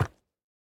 Minecraft Version Minecraft Version snapshot Latest Release | Latest Snapshot snapshot / assets / minecraft / sounds / mob / goat / step5.ogg Compare With Compare With Latest Release | Latest Snapshot
step5.ogg